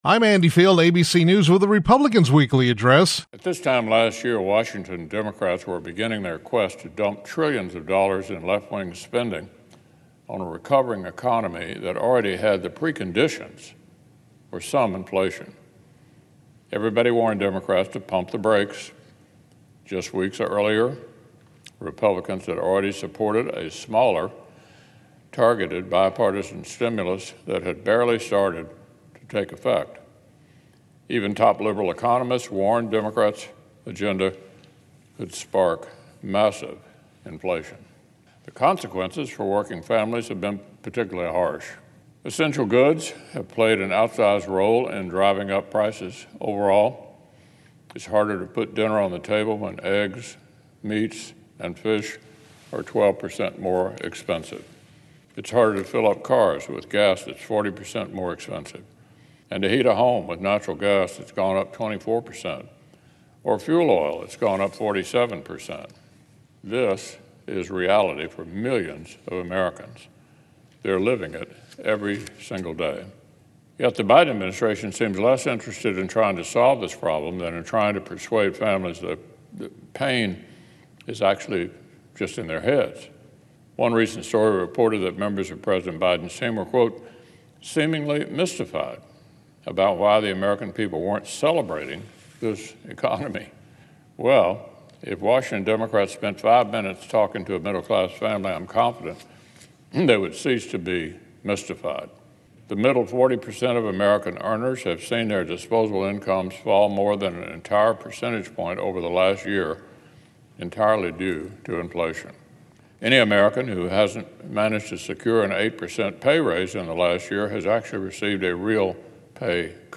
U.S. Senate Republican Leader Mitch McConnell (R-KY) delivered remarks on the Senate floor regarding inflation.